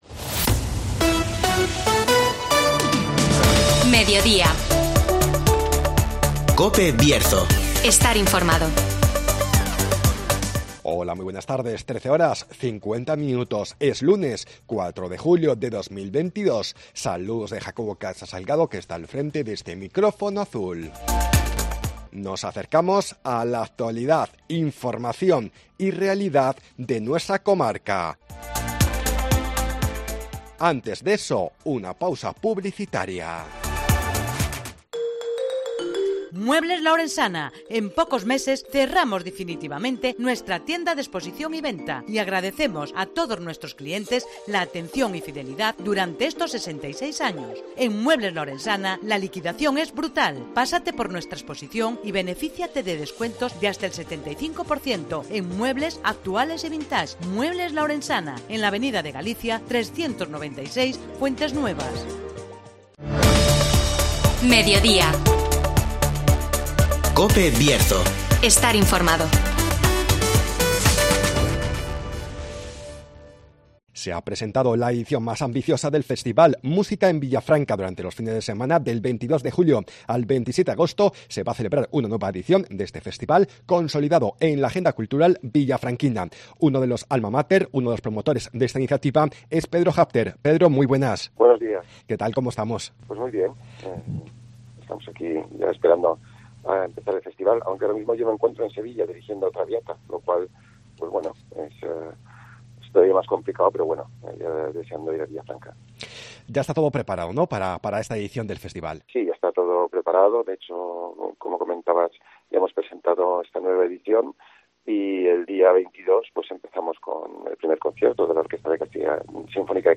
Se presenta la edición más ambiciosa del festival Música en Villafranca (Entrevista a Pedro Halffter)